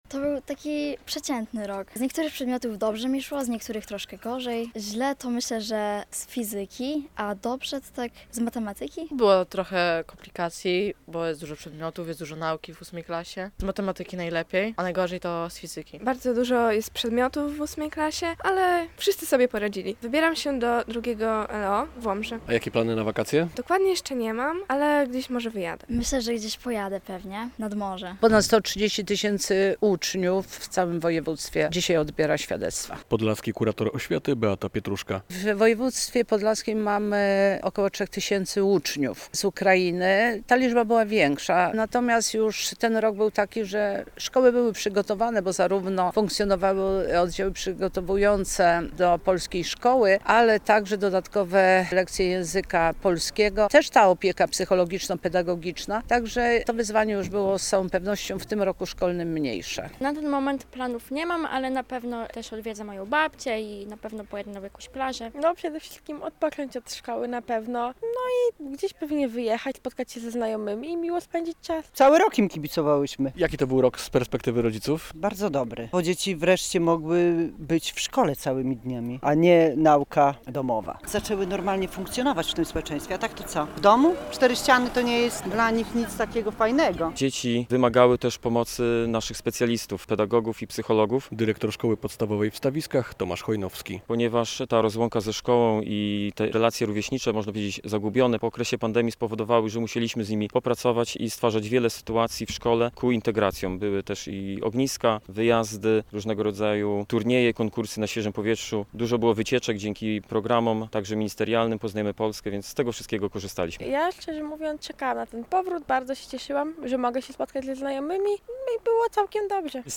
Uroczyste zakończenie roku szkolnego w Stawiskach - relacja